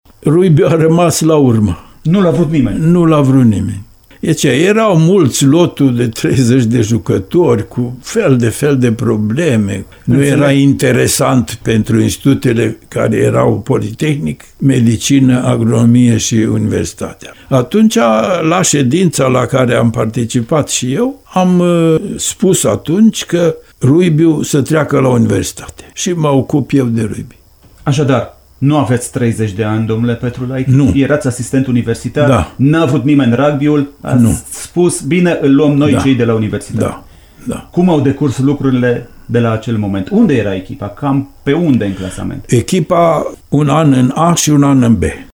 Interviu-serial